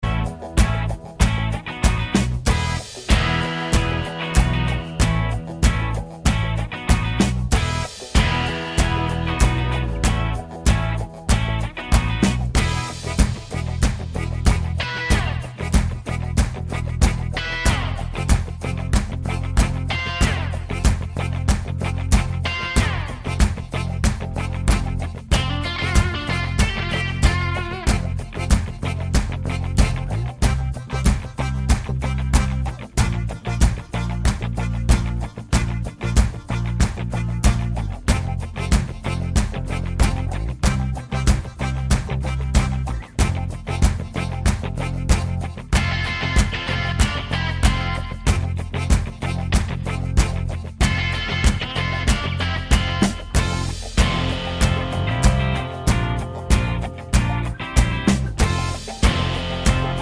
no Backing Vocals Glam Rock 2:52 Buy £1.50